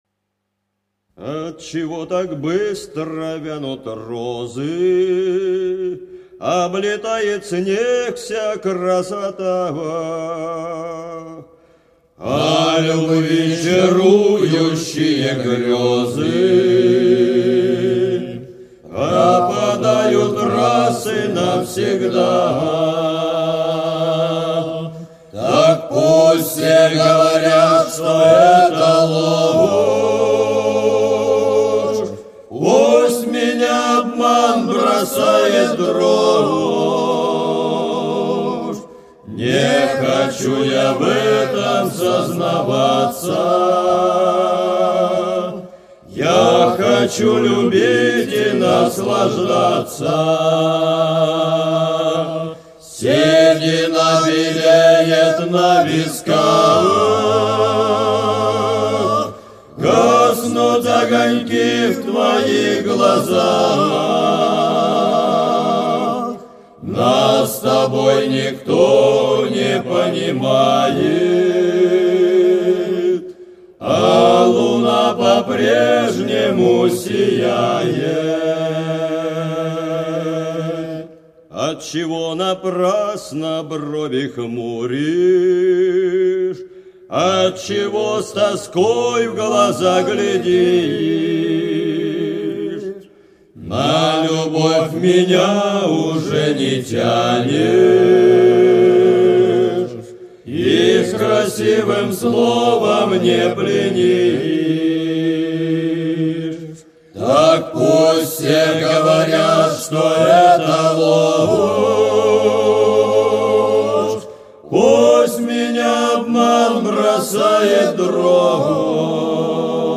Ансамбль Казачий Круг создан в Москве в 1986 году.
Виктор Татарский высказывается, что в исполнении Казачьего Круга эта песня похожа на грузинское многоголосие - по-моему, это не вредит песне, очень даже неплохо!